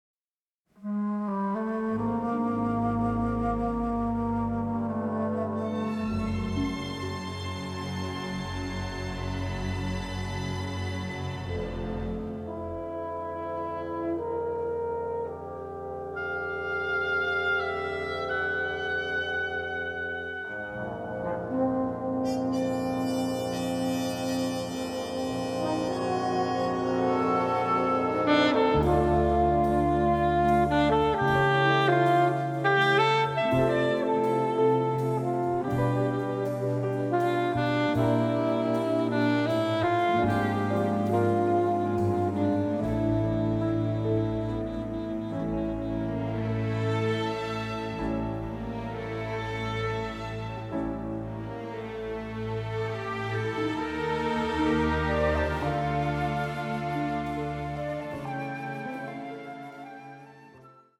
noir score
beautiful noir theme for saxophone
record the music in Paris